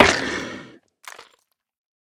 Minecraft Version Minecraft Version snapshot Latest Release | Latest Snapshot snapshot / assets / minecraft / sounds / mob / turtle / death3.ogg Compare With Compare With Latest Release | Latest Snapshot